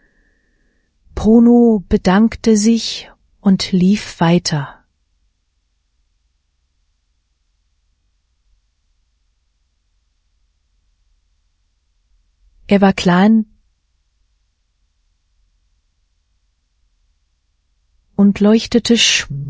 Here a unseen sample with a children's book and a speaker from unseen data with more emotions: